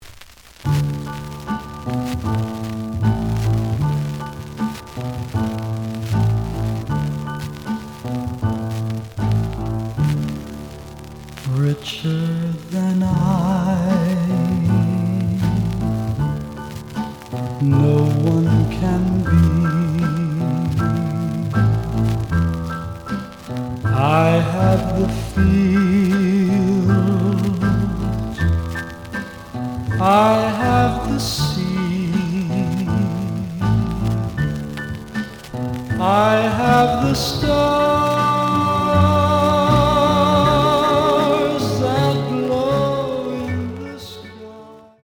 The audio sample is recorded from the actual item.
●Genre: Rock / Pop
Some noise on B side.